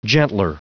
Prononciation du mot gentler en anglais (fichier audio)
Prononciation du mot : gentler